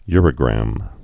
(yrə-grăm)